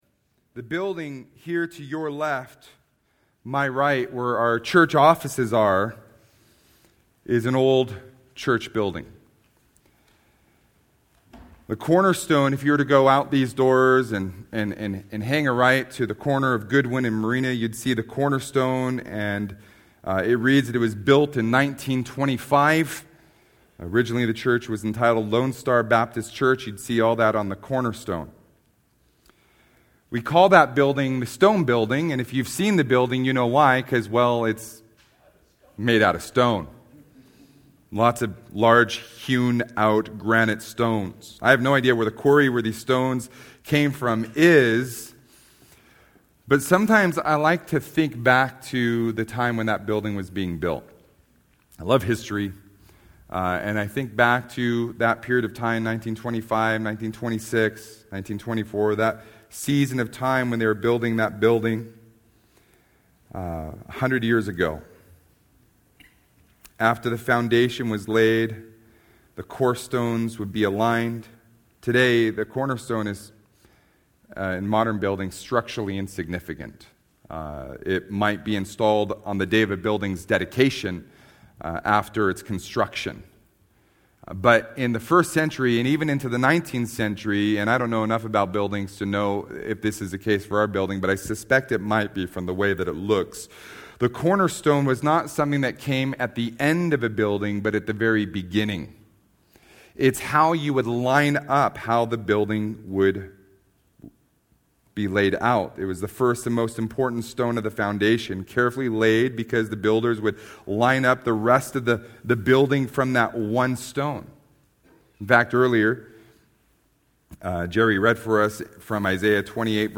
Sermons - Solid Rock Christian Fellowship